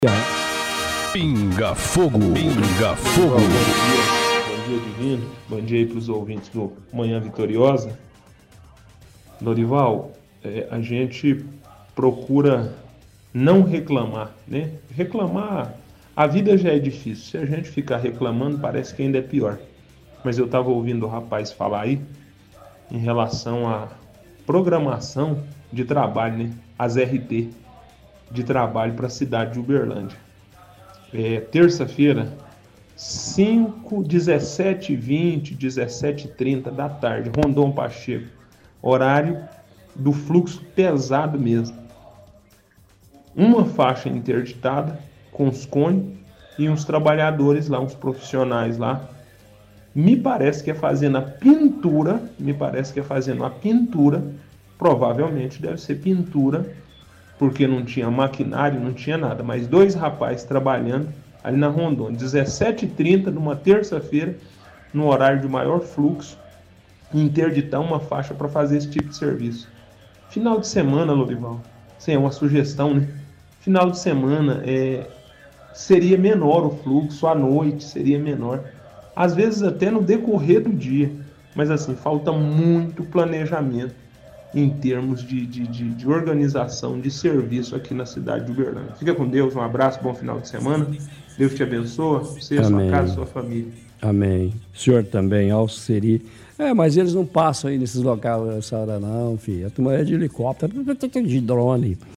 – Ouvinte reclama da programação de trabalho da prefeitura, fala que na avenida Rondon Pacheco às 17h havia uma faixa interditada e os funcionários fazendo a pintura do viaduto, ele diz que falta planejamento em termos de organização de serviço em Uberlândia.